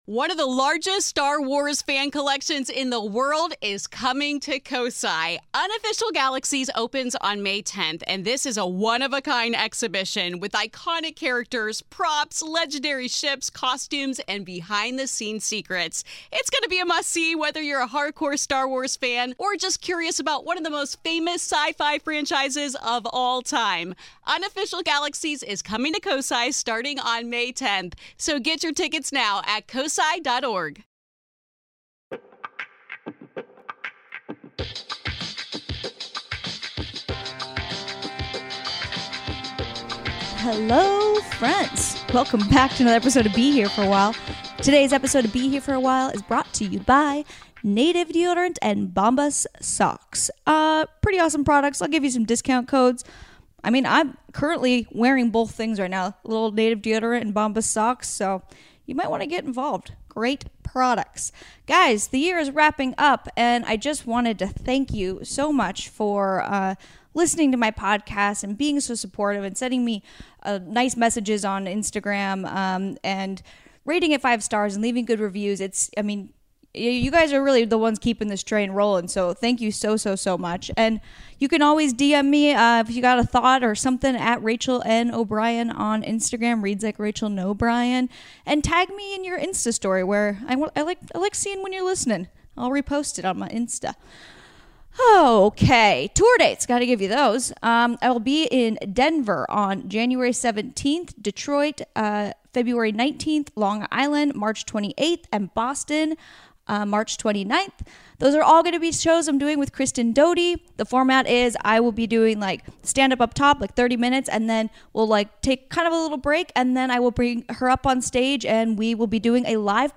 Education, Comedy, Comedy Interviews, Self-improvement